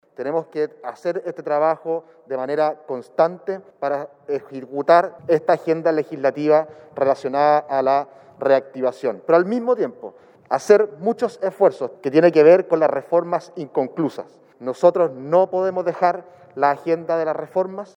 Su par de Renovación Nacional, el diputado Sebastián Torrealba, explicó que se debe trabajar de forma constante para ir avanzando en materias legislativas al interior de Chile Vamos.